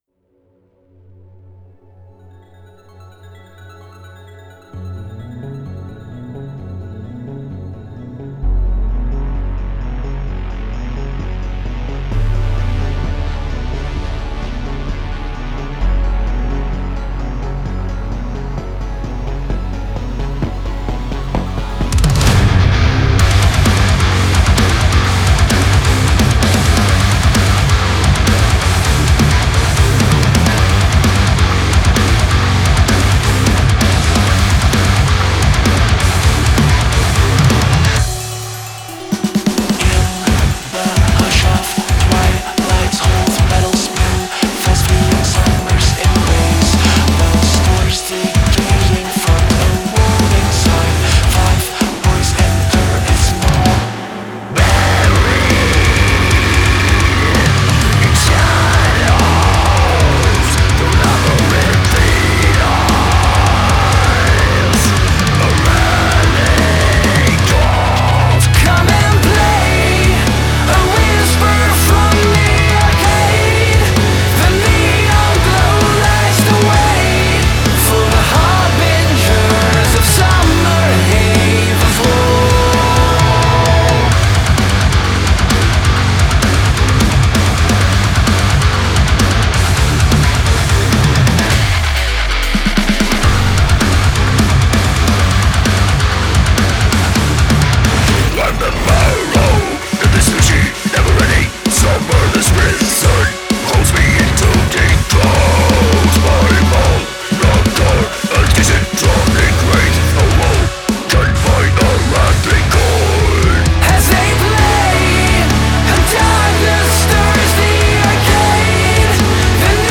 epic standalone single inspired by 80s adventure horror
lead guitar, vocals, orchestra
drums
bass
rhythm guitar